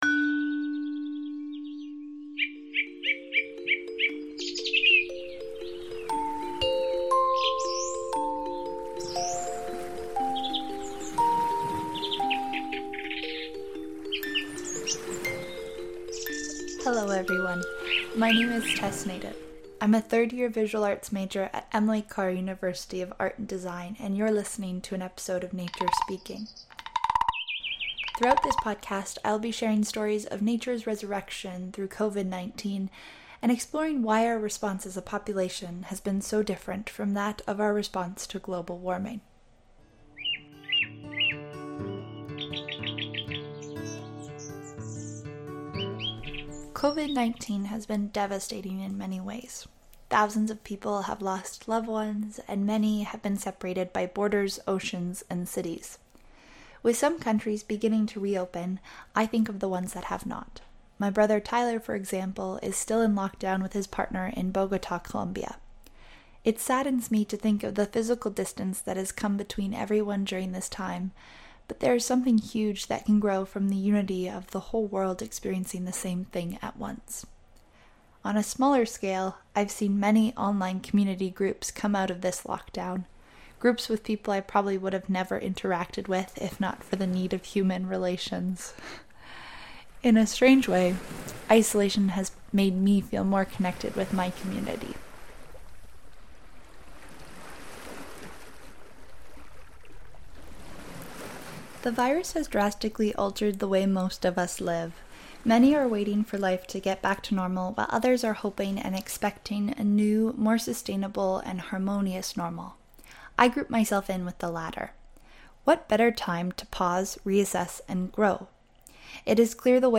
Audio non-musical